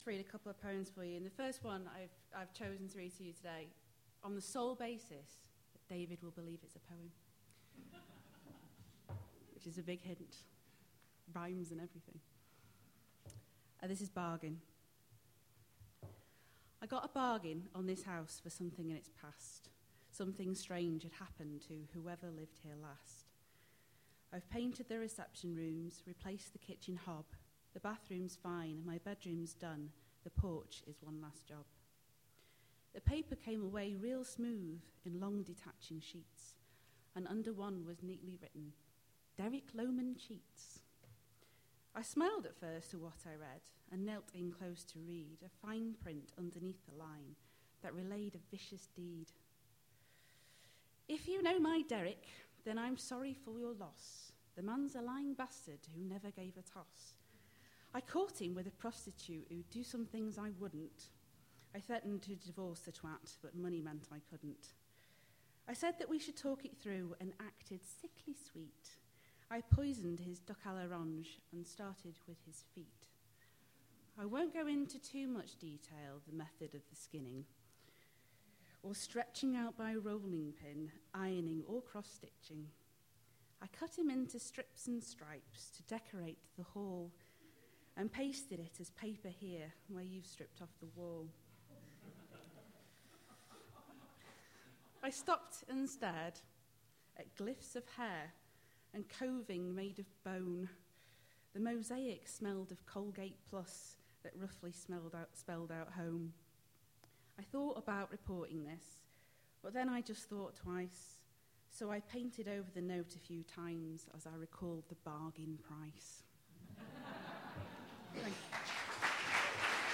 We4Poets Live at the IABF, Manchester